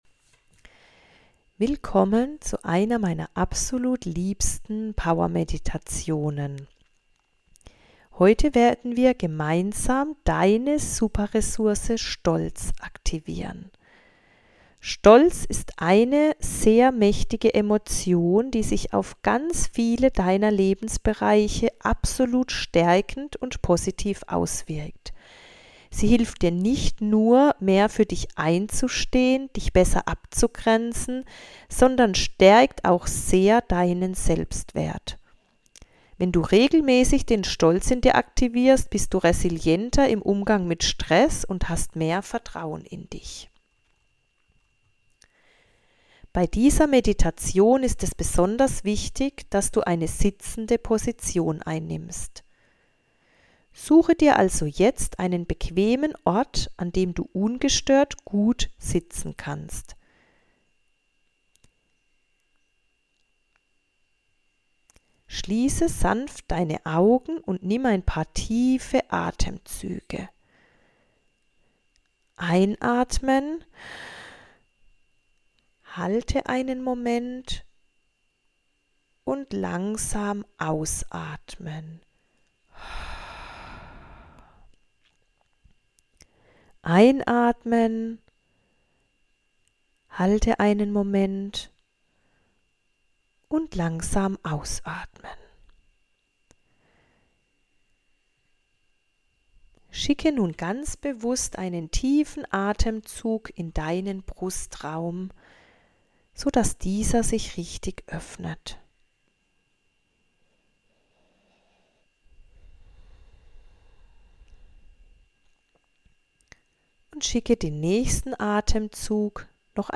Diese kraftvolle Meditation hilft dir, dein Selbstbewusstsein von innen heraus zu stärken und zwar mit einem völlig anderen Ansatz, als du es bisher kennst.